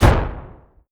EXPLOSION_Arcade_01_mono.wav